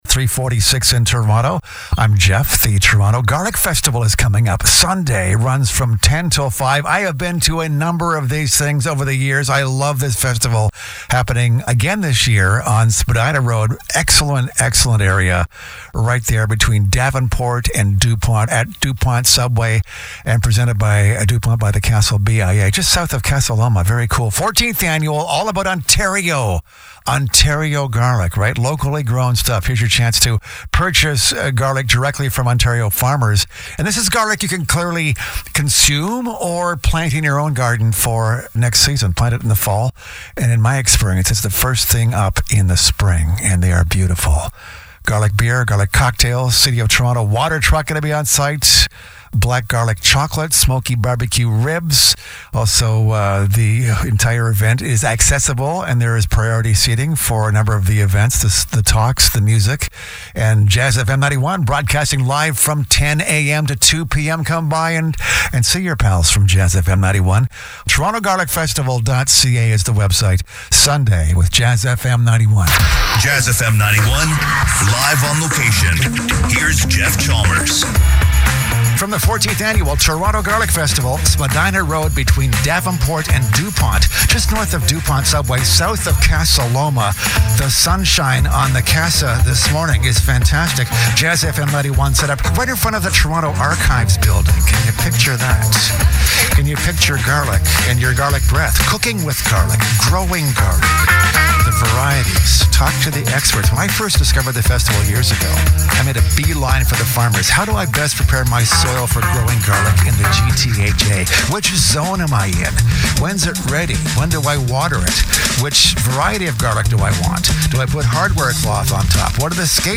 Live On Air at Toronto Garlic Festival – Sept 28, 2025